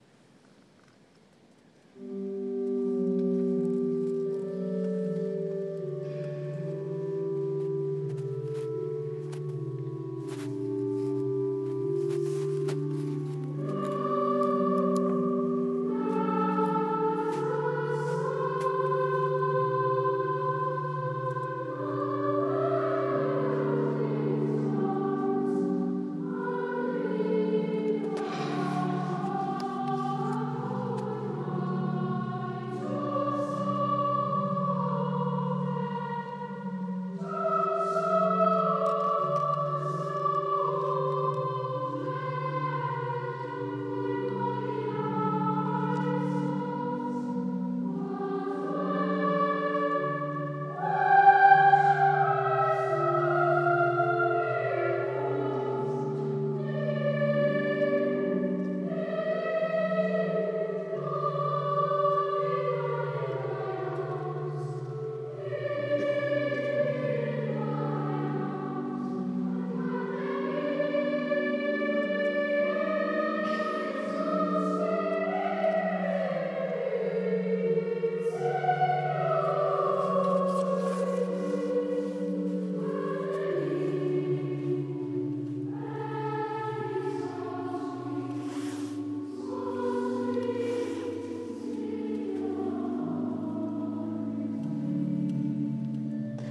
Boys' Choir of All Saints' Church giving a concert in The Cathedral of Haderslev.